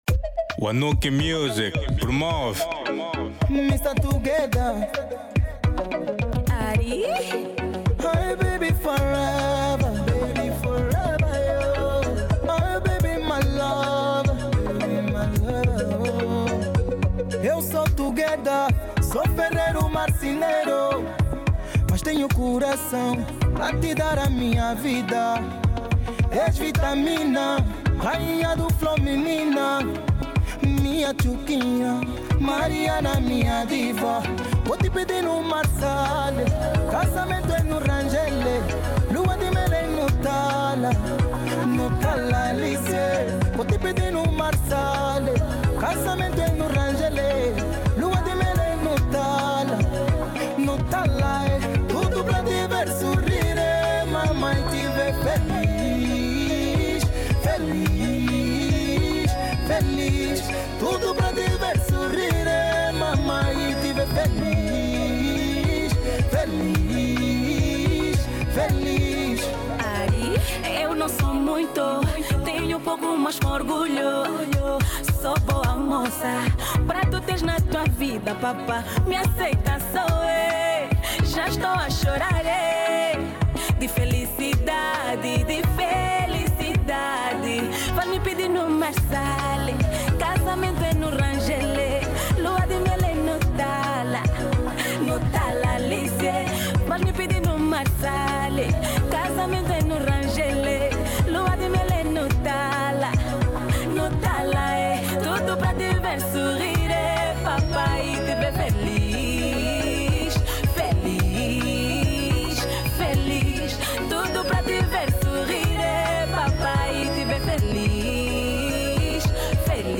Genero: Semba